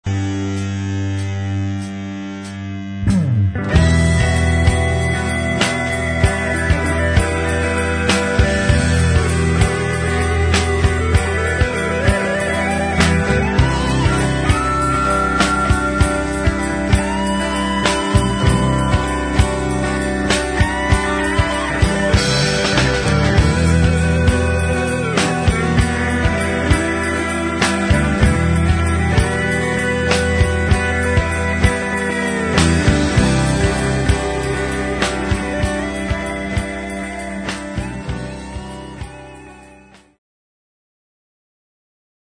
Groupe Rock PAU